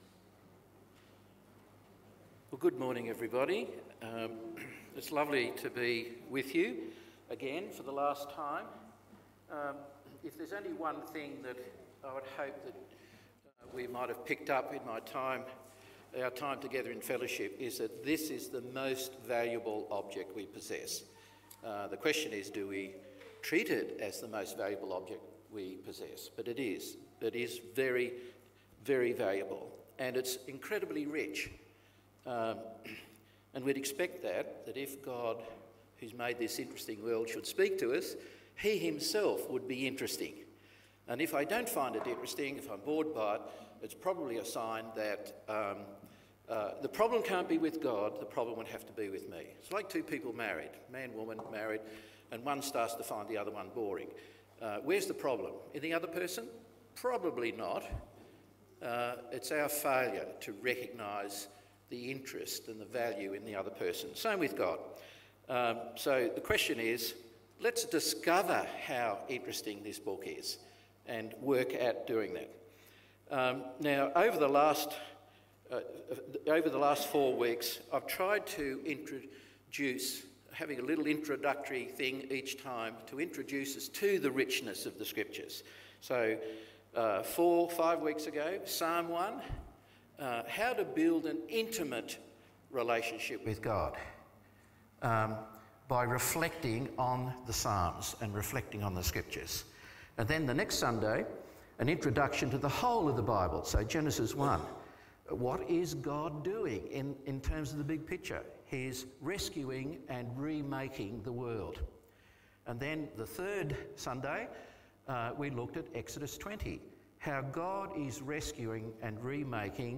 The final in a series of five sermons.
Service Type: AM Service